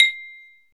MLT BELL B00.wav